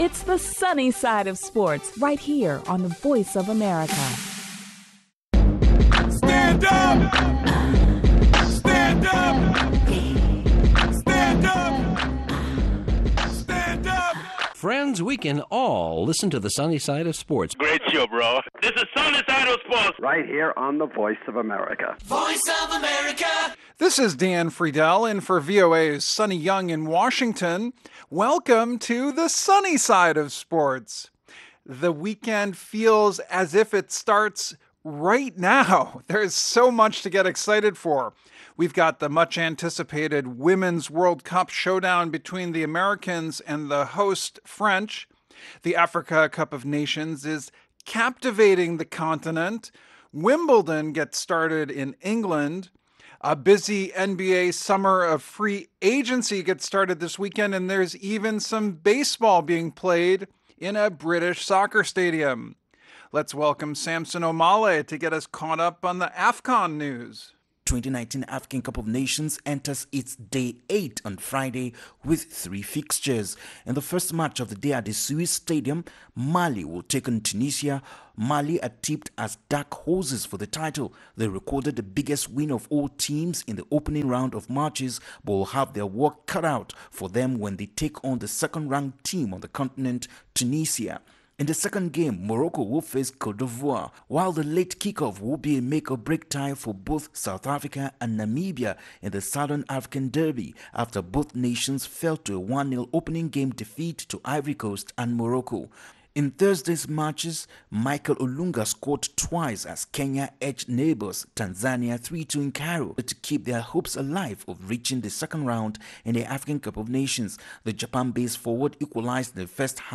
Airing Monday through Friday, this 30-minute program takes a closer look at the stories Africans are talking about, with reports from VOA correspondents, and interviews with top experts and...